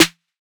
SOUTHSIDE_snare_og_crunch.wav